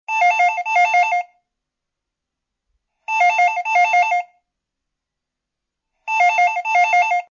Android, Klassisk, Klassisk Telefon